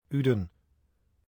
Uden (Dutch pronunciation: [ˈydə(n)]